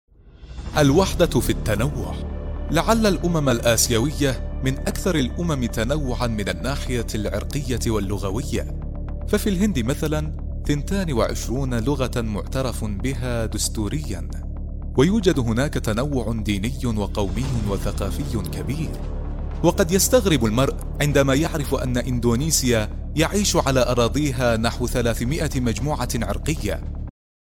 Male
Adult
Explainer